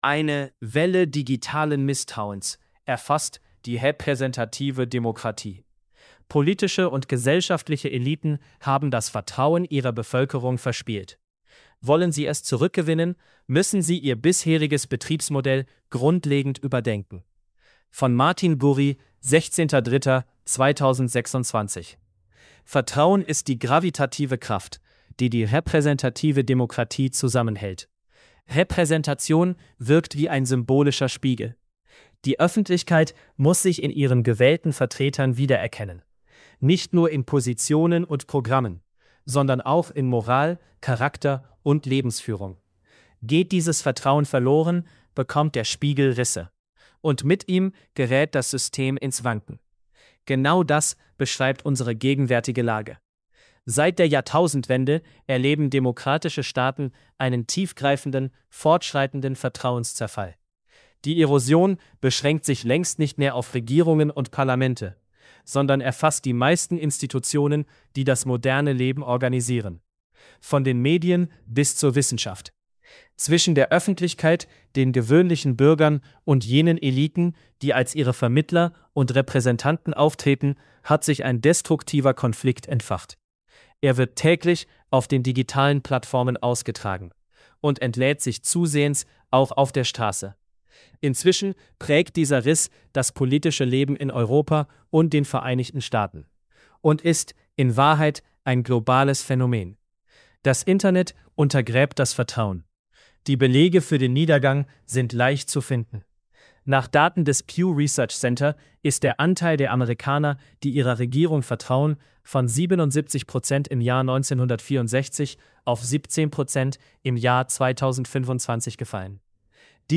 gurri_deutsch_male.mp3